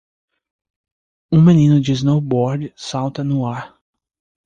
Pronunciado como (IPA) /ˈsaw.tɐ/